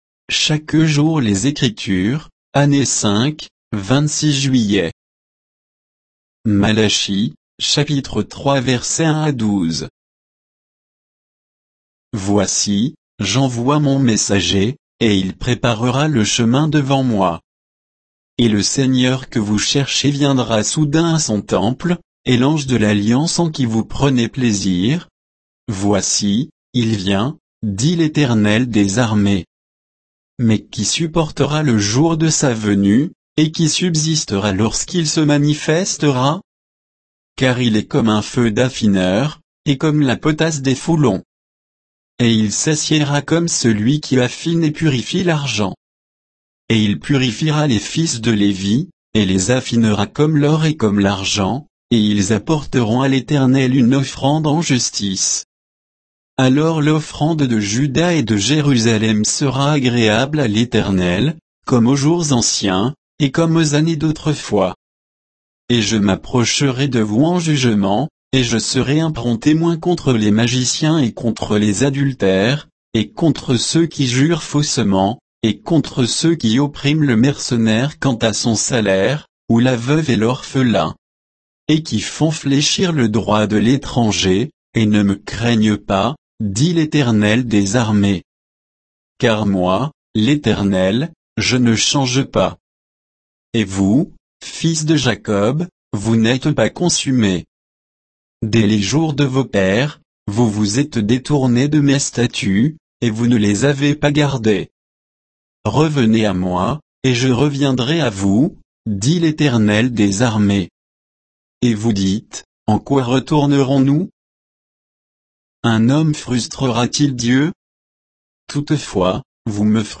Méditation quoditienne de Chaque jour les Écritures sur Malachie 3